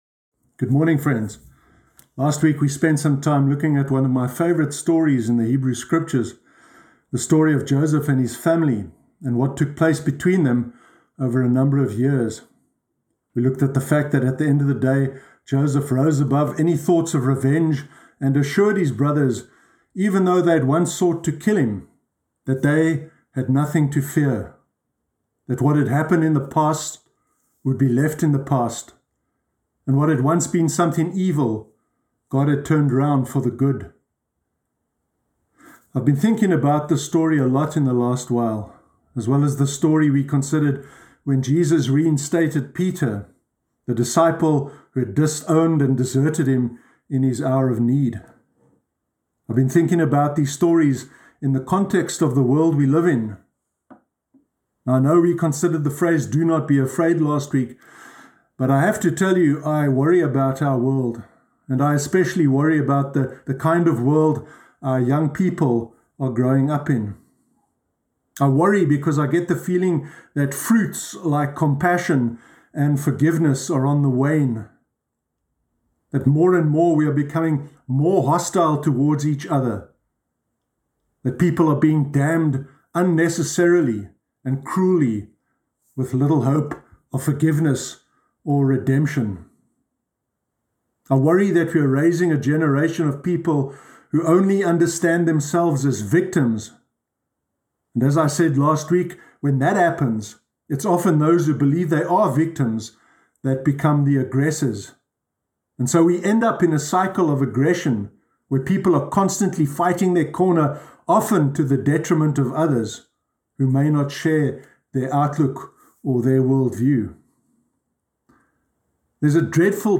Sermon Sunday 18 April 2021